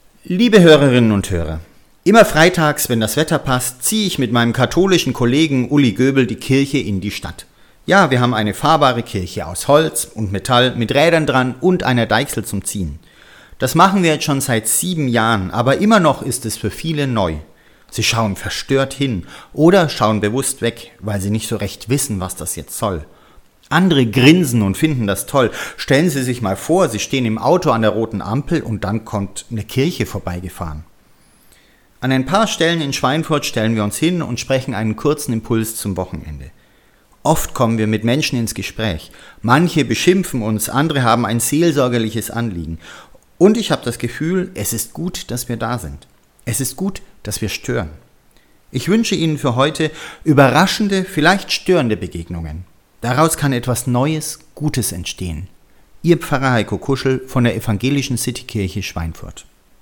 Kurze Impulse zum Nachdenken fürs Wochenende.